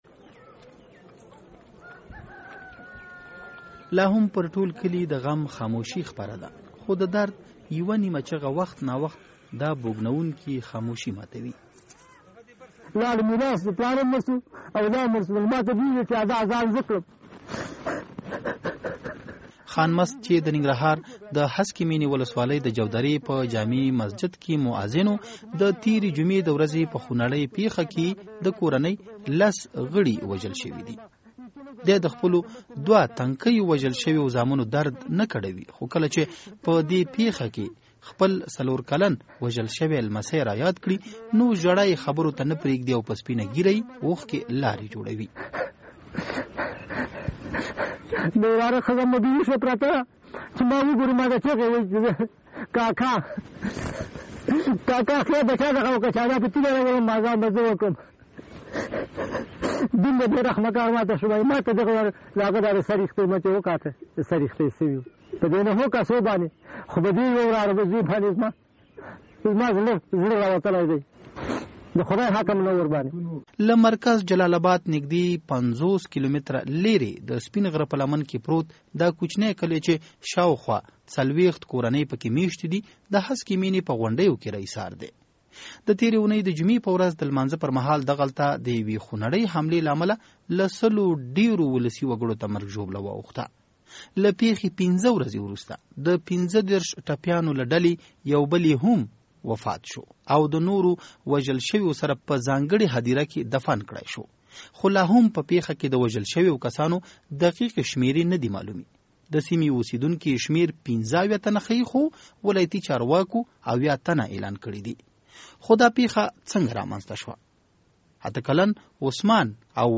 د جودرې راپور